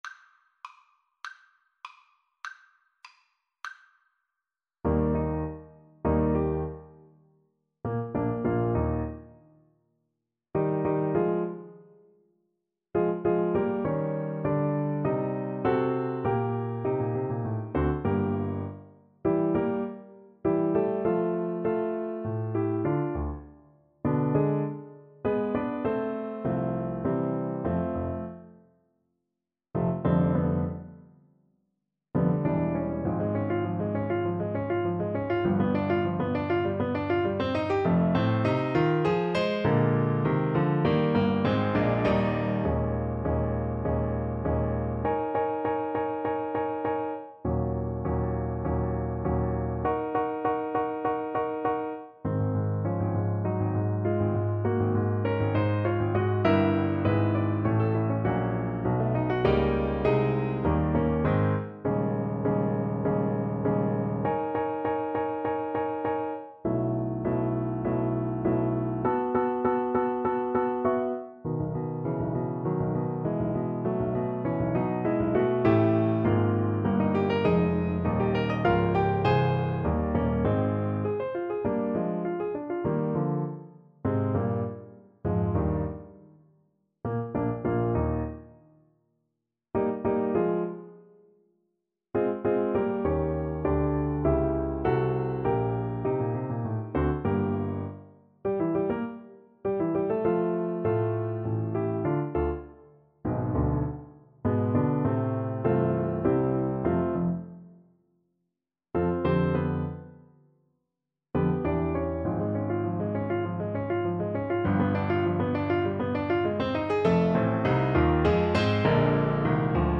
~ = 200 Allegro Animato (View more music marked Allegro)
Classical (View more Classical Saxophone Music)